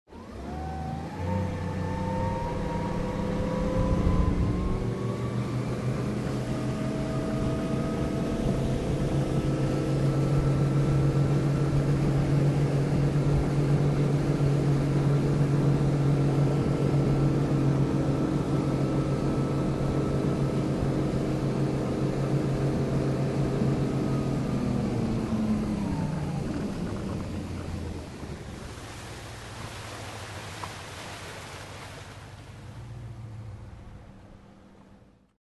Шум разгона яхты